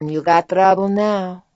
gutterball-3/Gutterball 3/Commentators/Natasha/nat_yougotroublenow.wav at 0b195a0fc1bc0b06a64cabb10472d4088a39178a